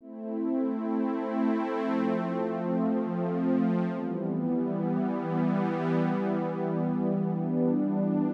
MOO String Riff ACDFEG.wav